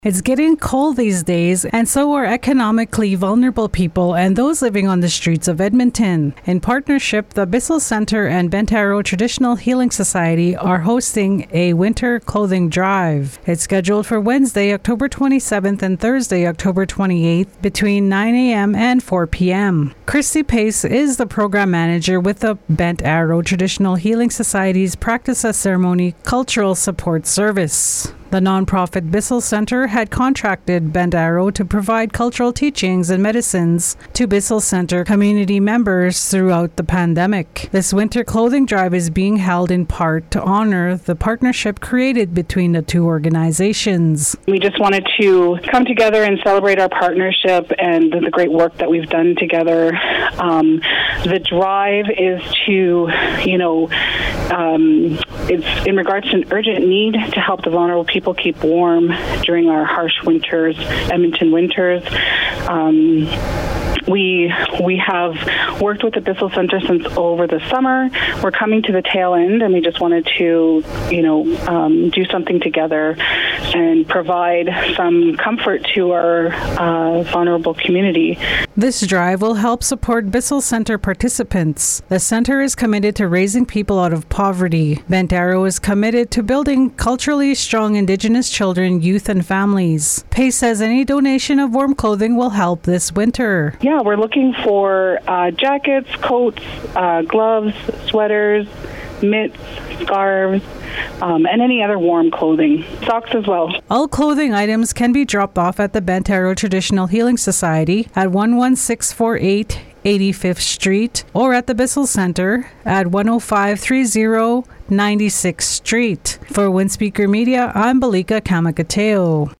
Radio_doc_winter-clothing_updated.mp3